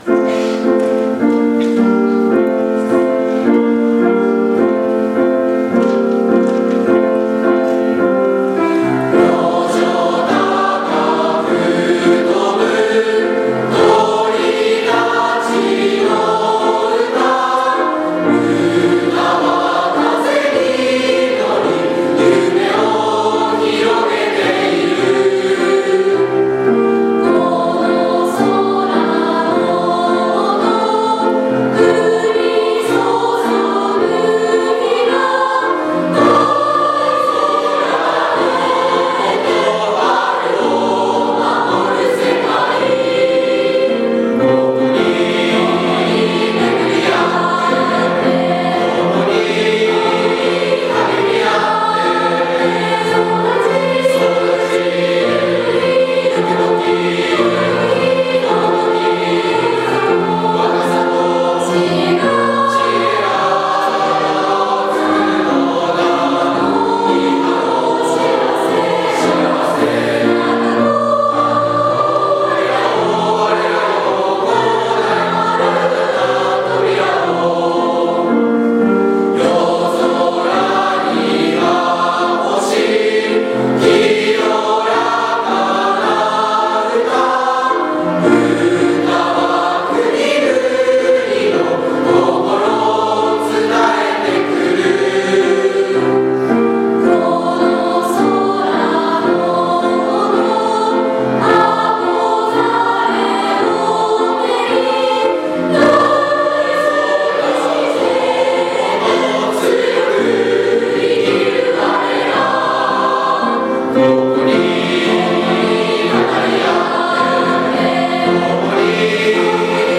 作詞：中山　知子　　　作曲：蒔田　尚昊
第39回卒業式での校歌全校合唱
第39回卒業式の全校混声三部合唱　平成27年３月13日
koukagassyou.mp3